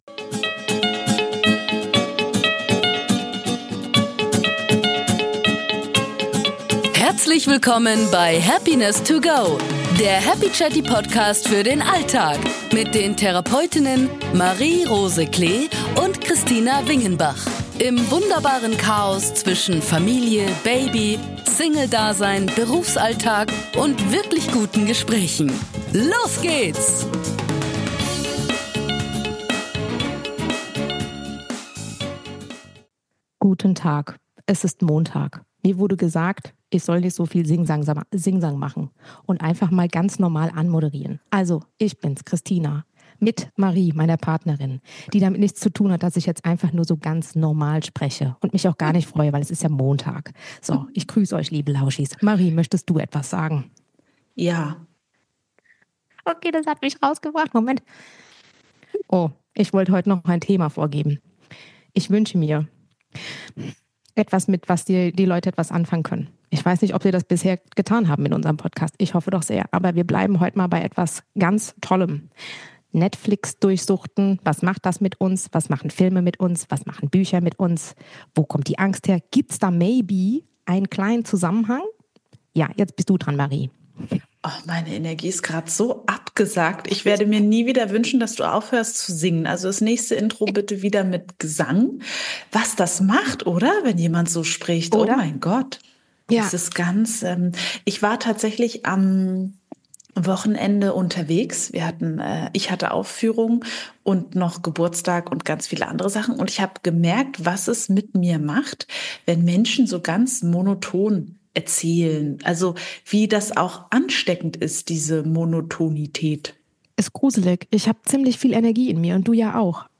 In "Happiness to go" nehmen euch zwei Therapeutinnen mit auf eine Reise durch die Höhen und Tiefen des Alltags und der Therapie.